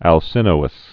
(ăl-sĭnō-əs)